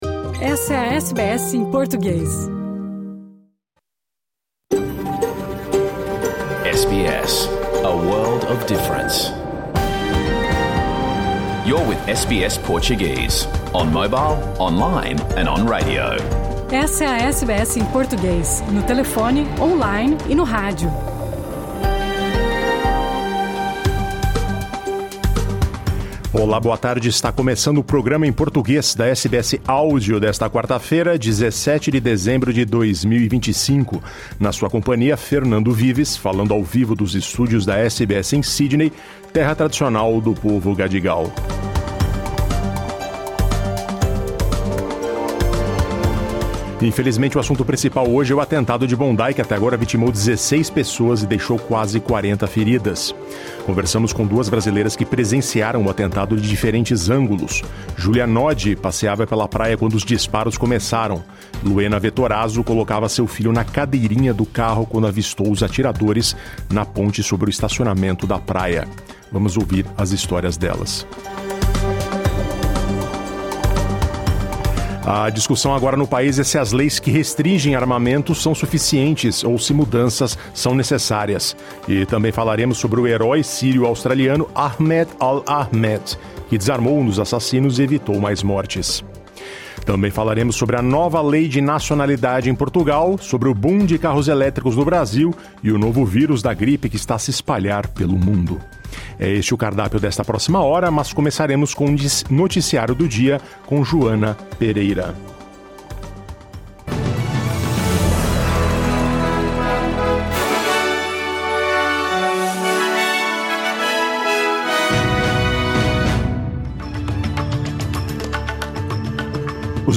O programa em português que foi ao ar ao vivo pela rádio SBS 2 em toda a Austrália nesta quarta-feira, 17 de dezembro.
Conversamos com duas brasileiras que presenciaram o atentado de diferentes ângulos.